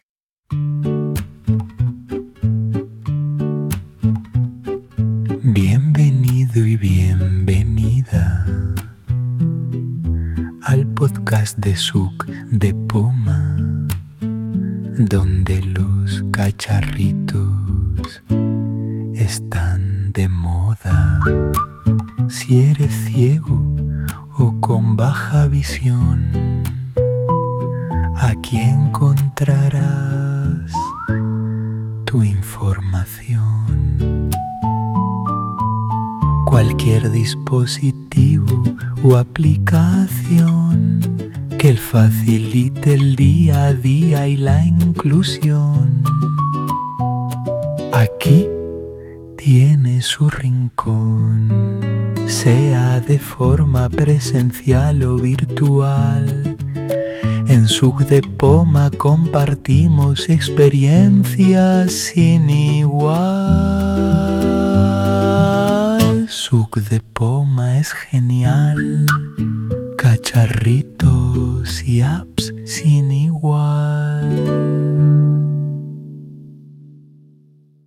Canciones de muestra con voces clonadas
Son cinco canciones en las que cantamos tres personas de las que conocéis sobradamente nuestras voces